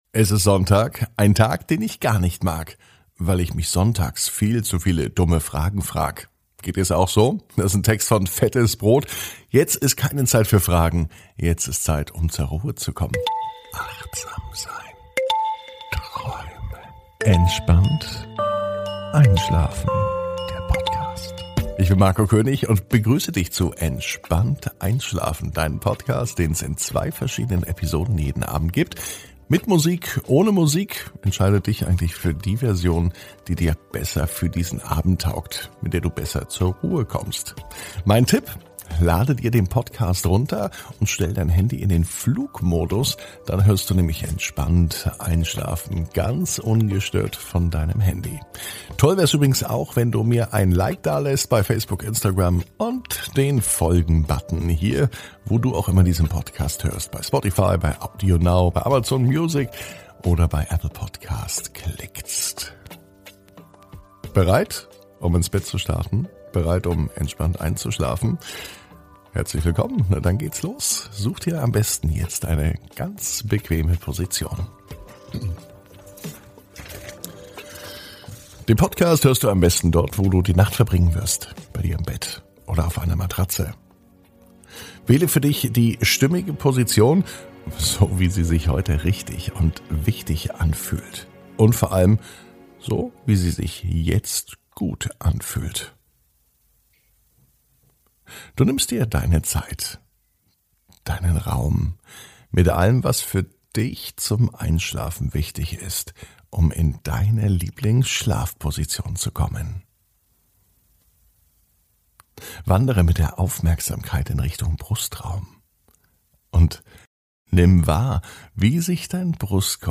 (ohne Musik) Entspannt einschlafen am Sonntag, 16.05.21 ~ Entspannt einschlafen - Meditation & Achtsamkeit für die Nacht Podcast